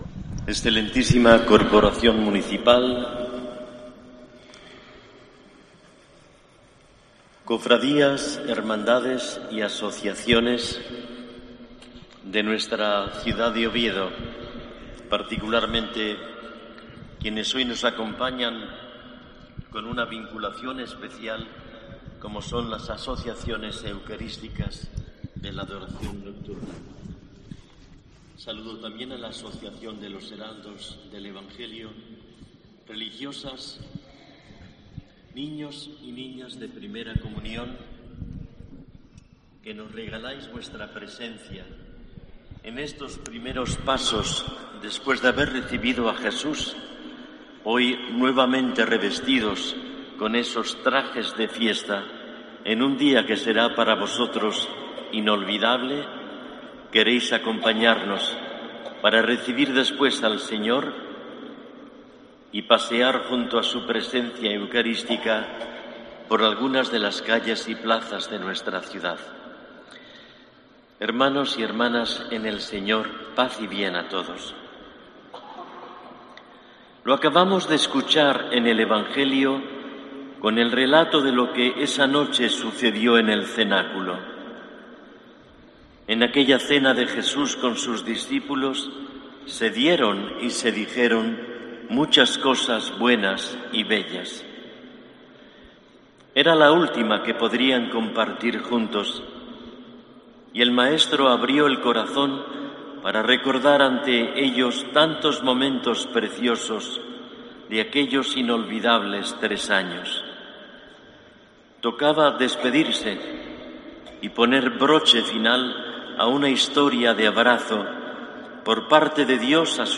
Homilía del Arzobispo de Oviedo, Jesús Sanz Montes, en la misa en la Catedral por el Corpus Christi